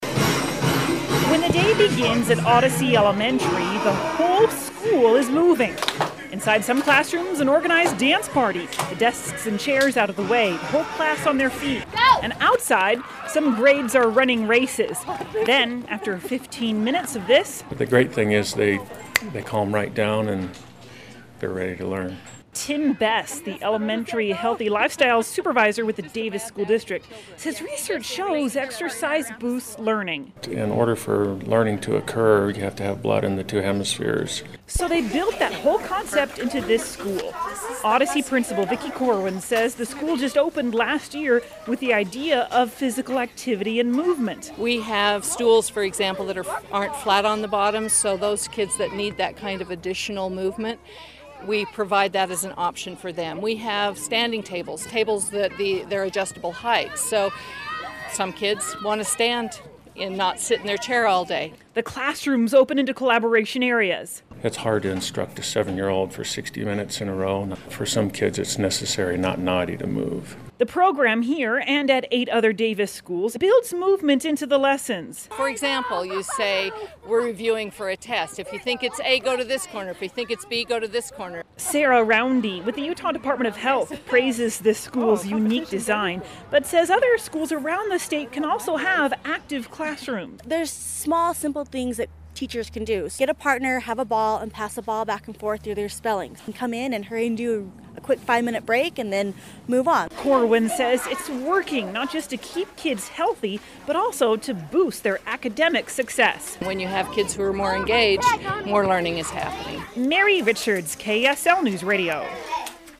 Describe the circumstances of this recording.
report from Woods Cross.